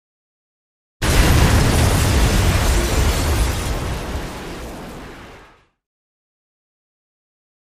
Explosion Heavy Glass Destruction Type 2 Version 1